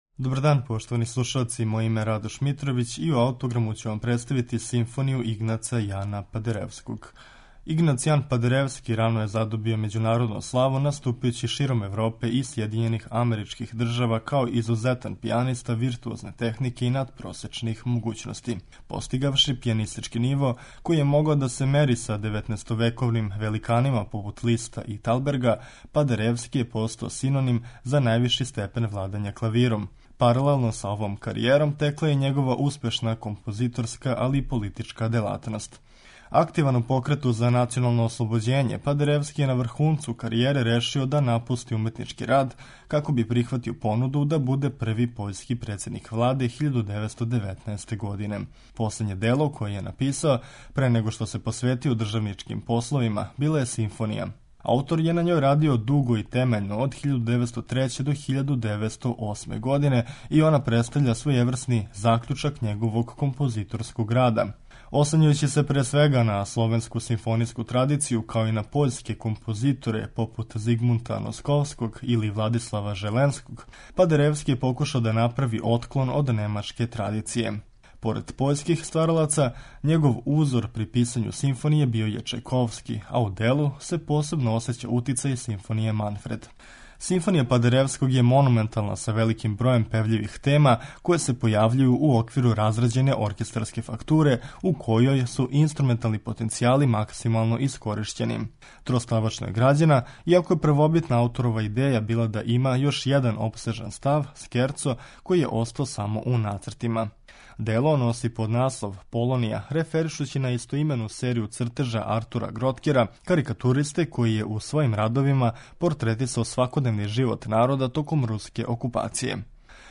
Монументална симфонија "Полонија", Игнаца Падеревског, јесте последње дело овог пољског композитора, пијанисте и државника.